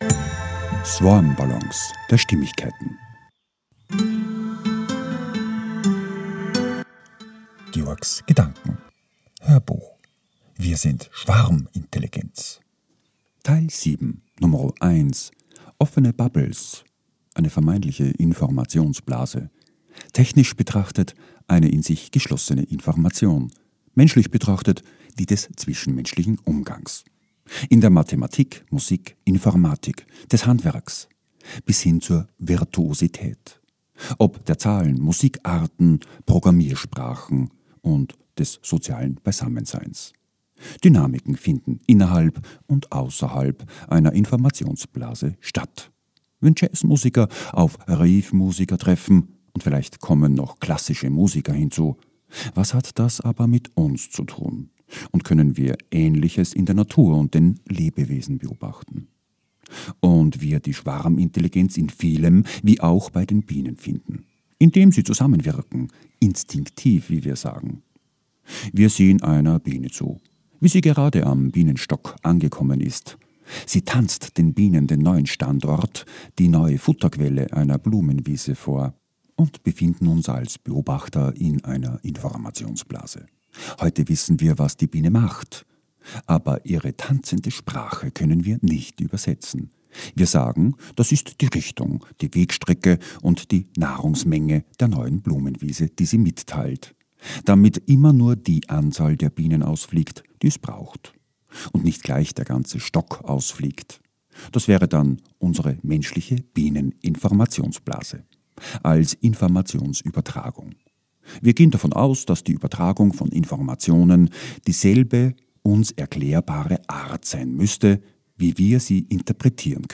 HÖRBUCH - 007.1 - WIR SIND SCHWARMINTELLIGENZ - OFFENE BUBBLES